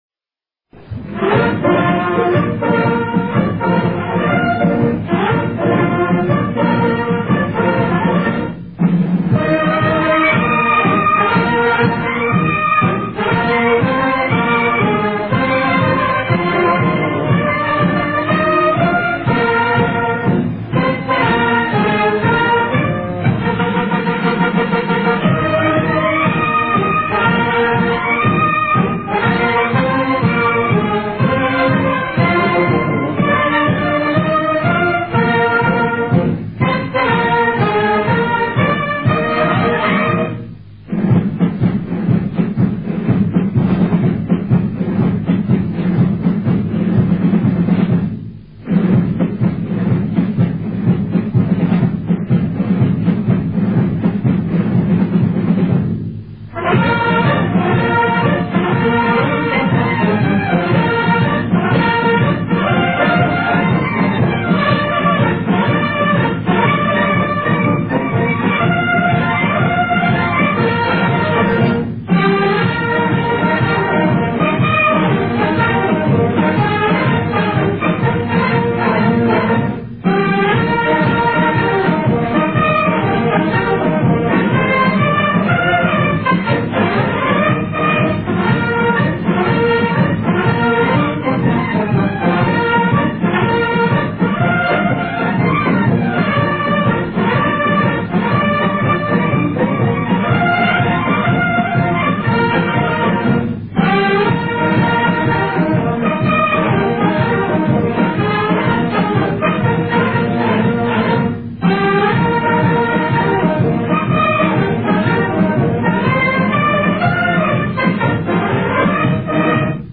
موسیقی جنگ